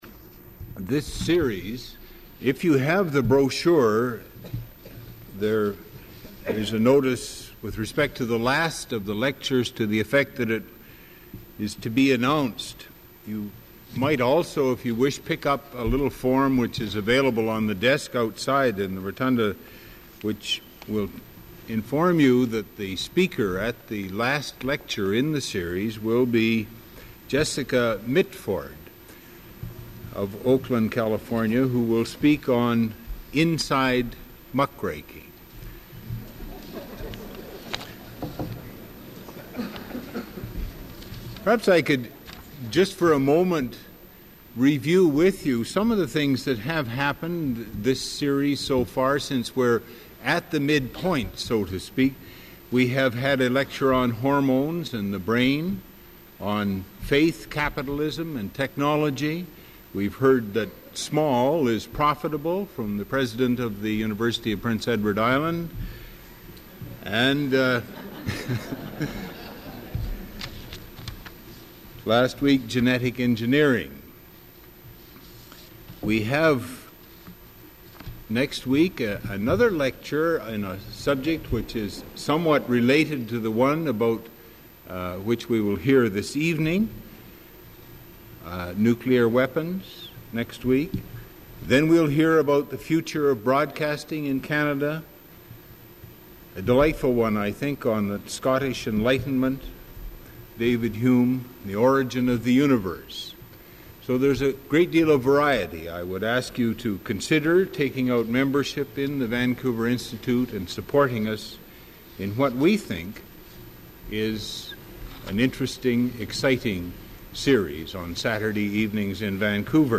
Original audio recording available in the University Archives (UBC AT 1041).